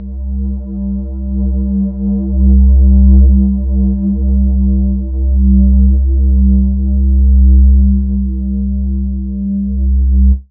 15Bass20.WAV